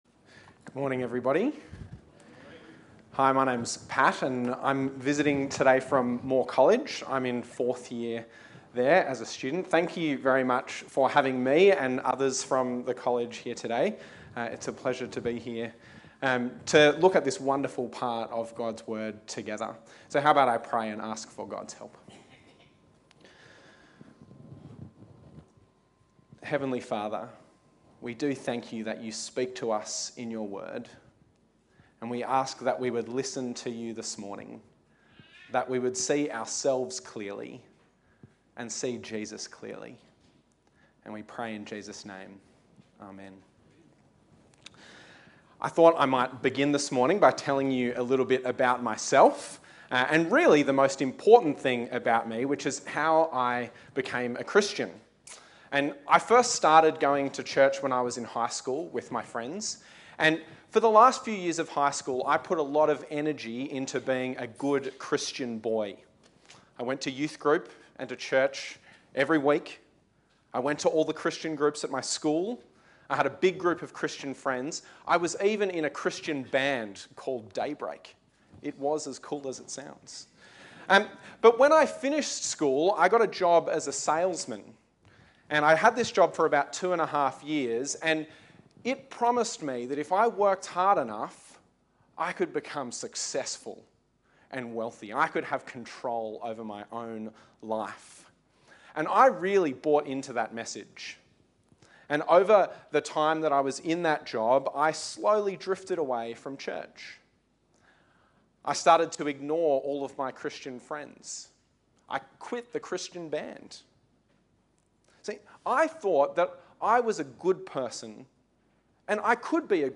SERMON – Jesus is Compassion